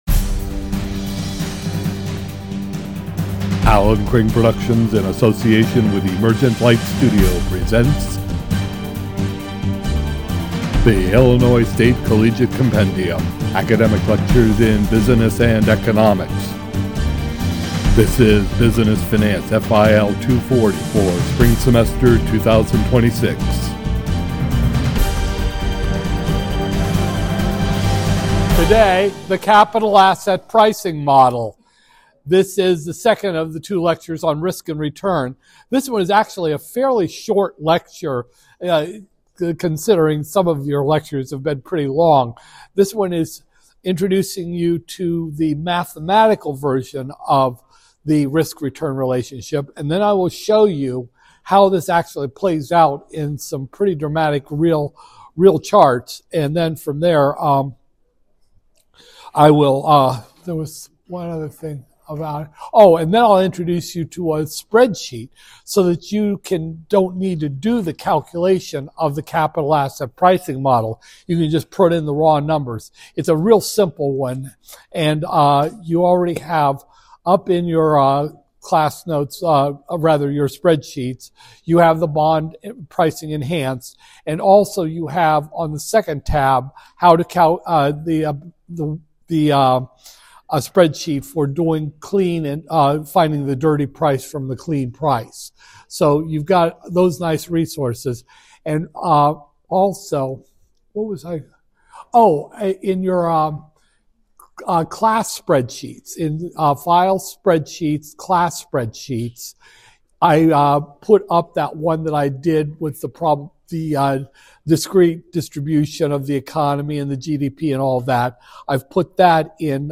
iTunes Podcast Click the graphic above to subscribe to the free podcasts of these lectures at Apple Podcasts.